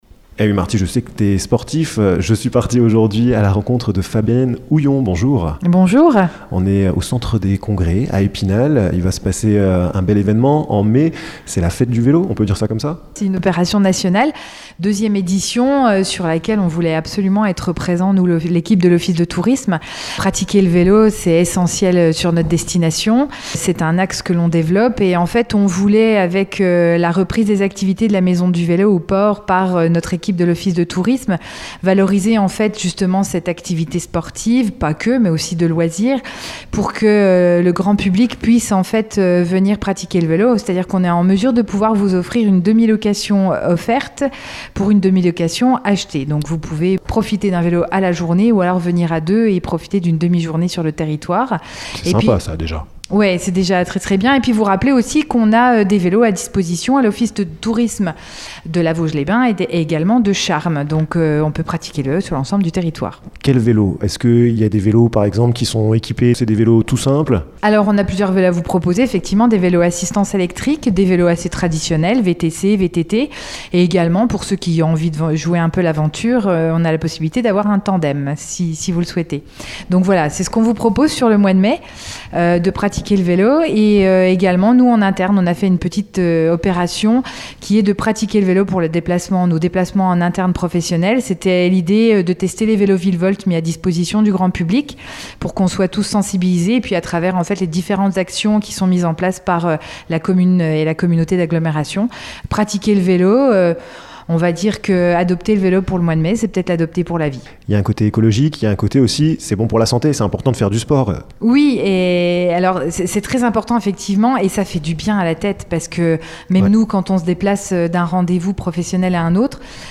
vous emmène en balade dans cette interview grâce à différents types de 2 roues!